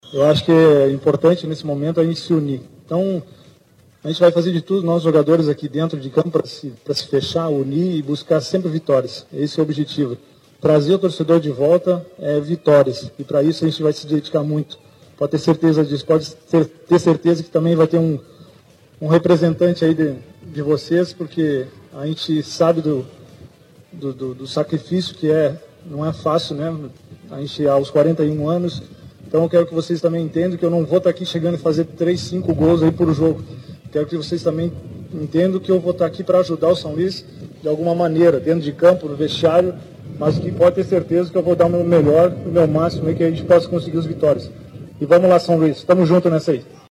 Paulo Baier faz discurso para torcida em apresentação